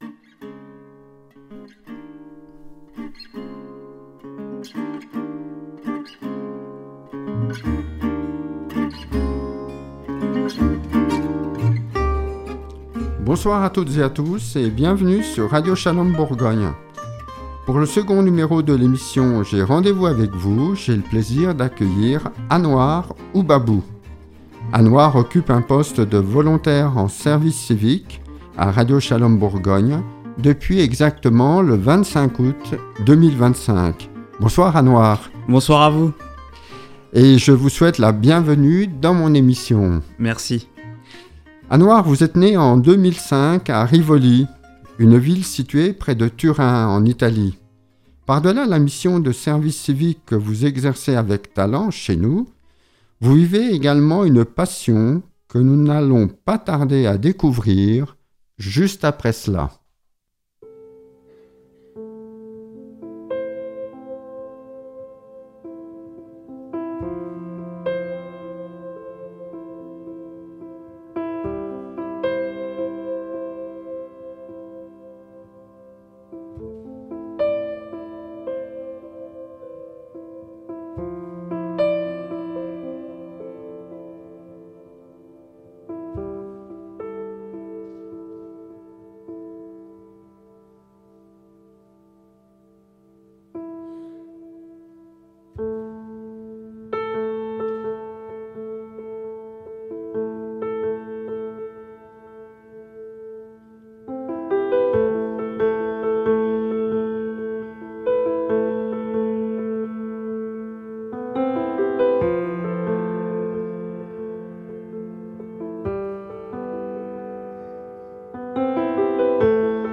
Un rendez-vous chaleureux et vibrant avec un musicien en pleine ascension, habité par une sensibilité profondément lumineuse.